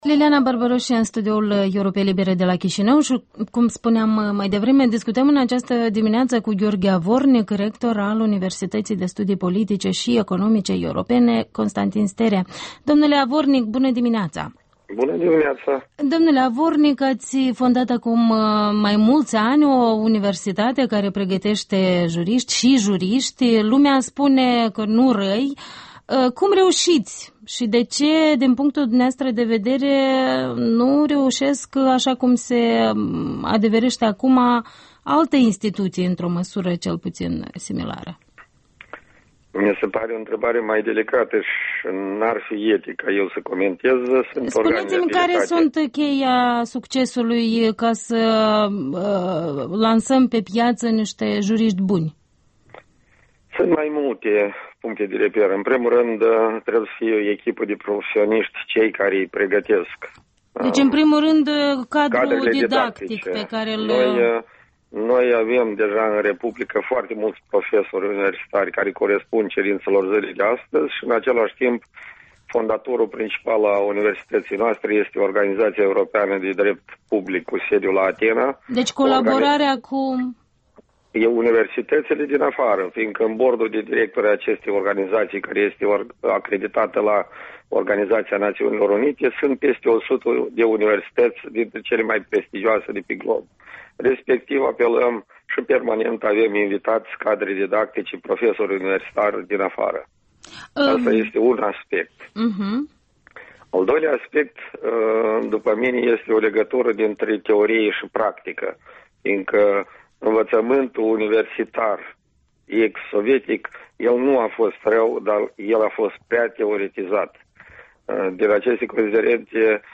Interviul matinal la Europa Liberă: cu Gheorghe Avornic